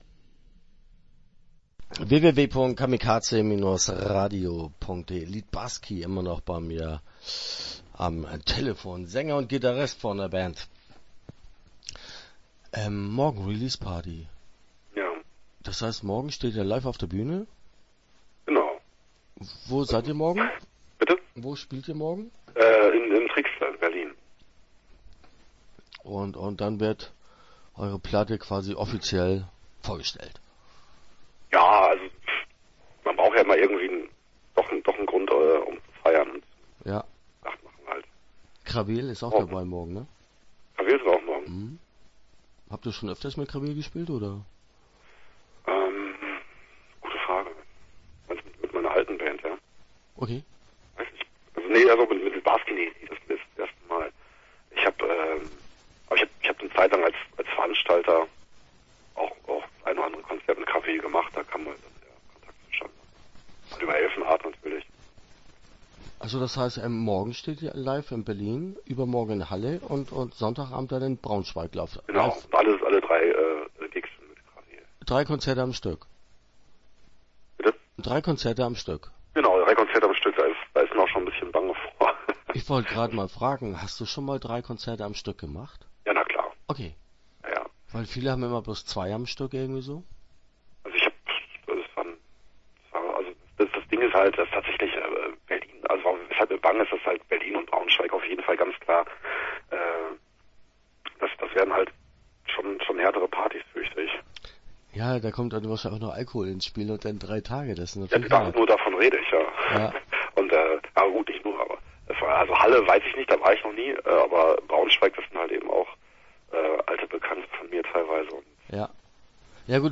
Litbarski - Interview Teil 1 (8:55)